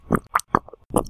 gurgle-bubble-liquid-2.ogg